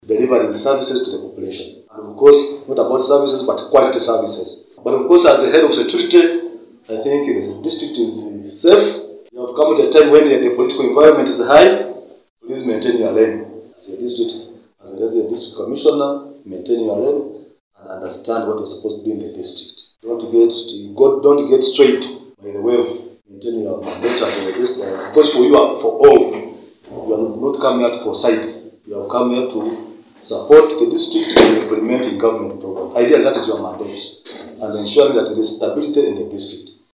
Speaking at the handover, Ronald Mutegeki, the Chief Administrative Officer of Kabale, called on Kyakashari to maintain integrity and unity. He emphasized that service delivery must remain the top priority and cautioned against being influenced by political agendas.